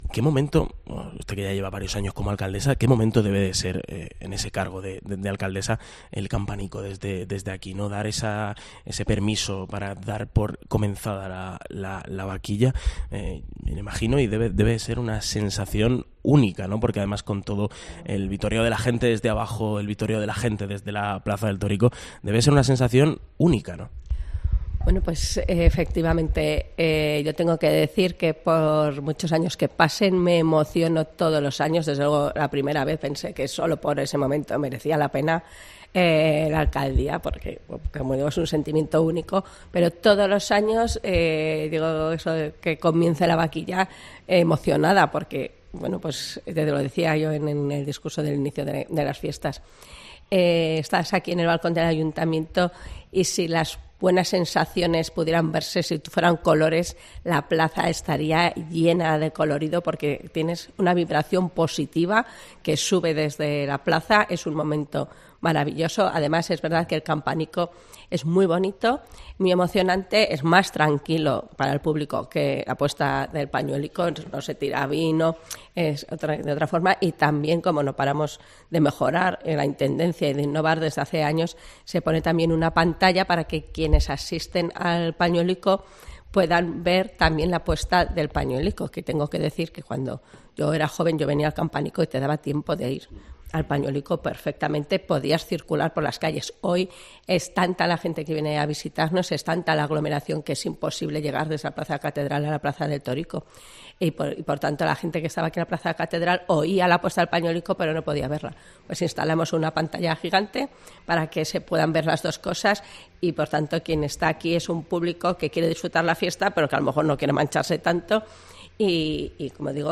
La alcaldesa de Teruel, Emma Buj, habla en COPE sobre lo que supone para ella el toque del campanico